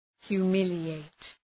{hju:’mılı,eıt}